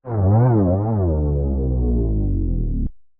boss-dies.mp3